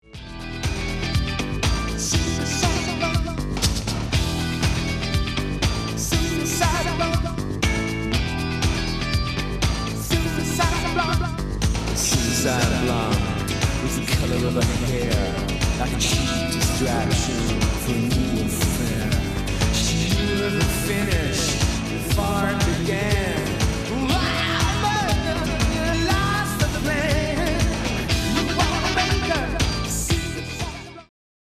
Midi Music